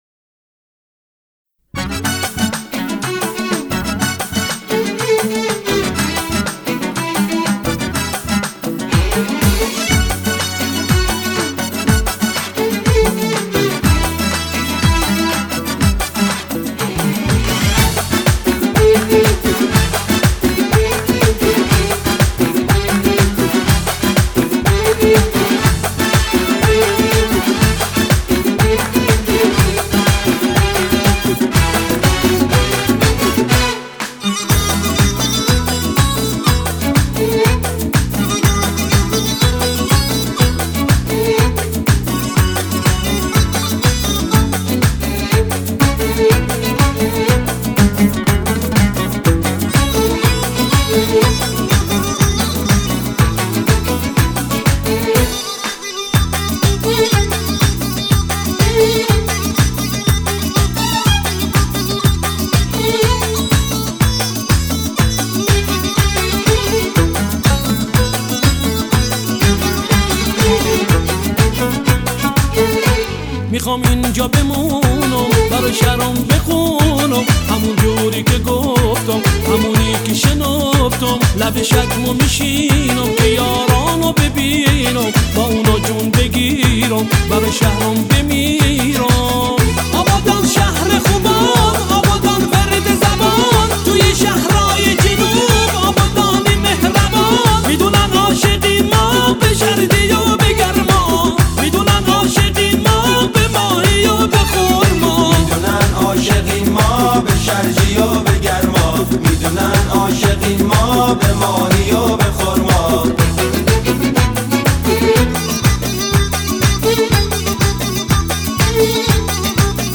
آهنگ بندري